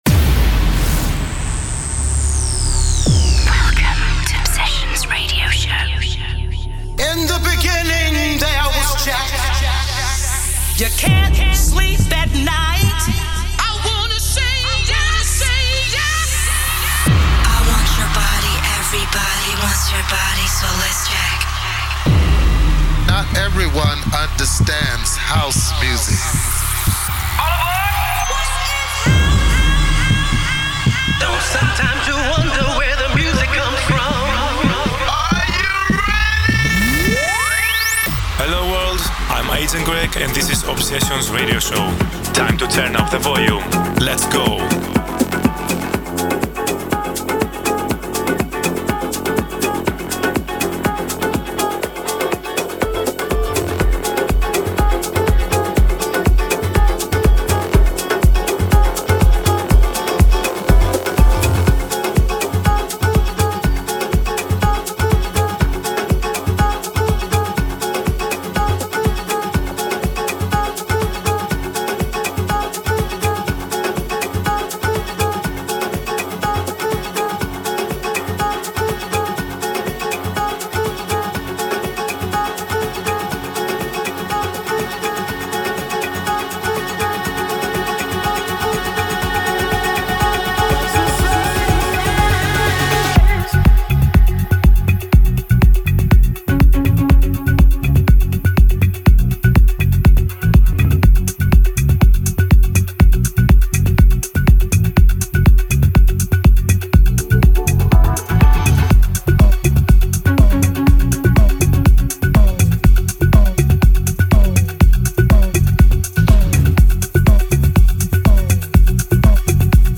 weekly 1 hour music mix
Expect nothing but pure House music.